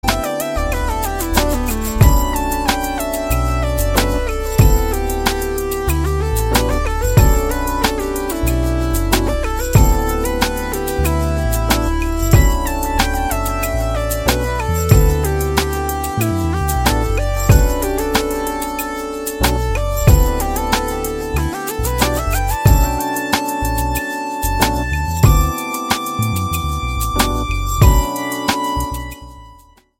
POP  (02.04)